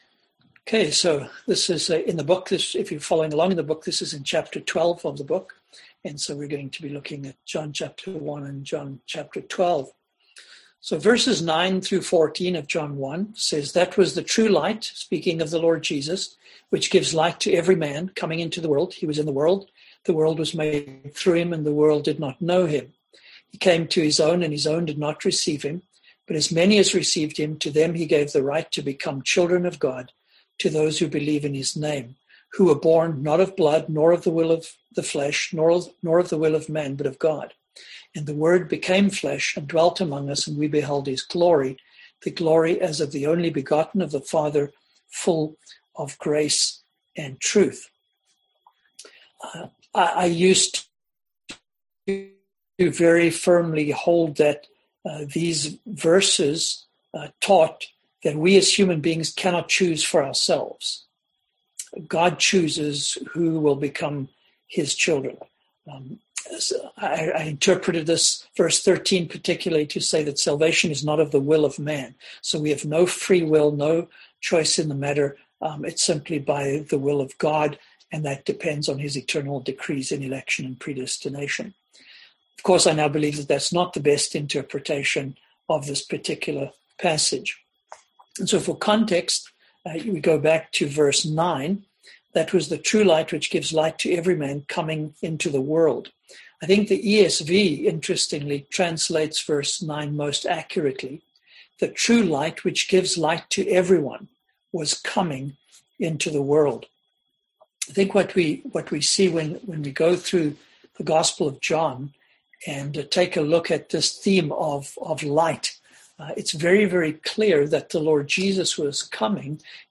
Service Type: Seminar Topics: Election , Predestination